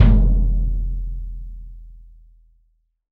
Index of /90_sSampleCDs/AKAI S6000 CD-ROM - Volume 3/Kick/GONG_BASS
GONG BASS3-S.WAV